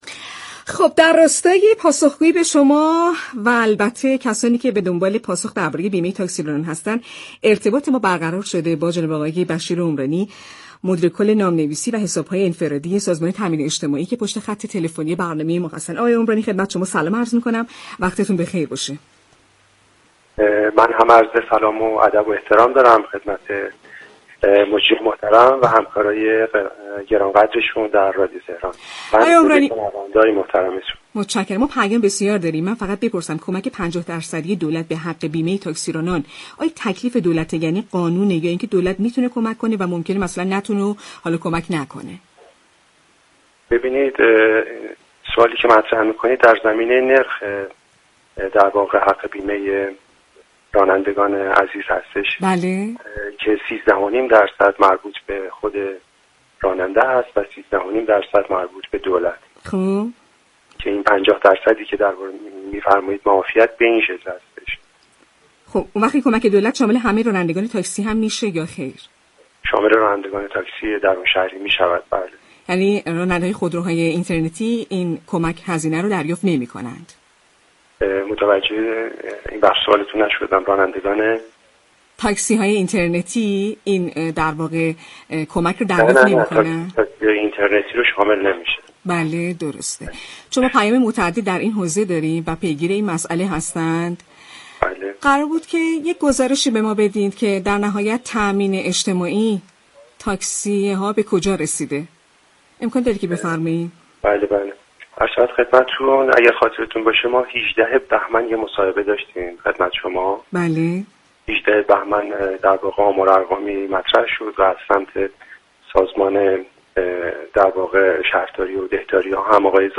در گفت و گو با «شهر آفتاب»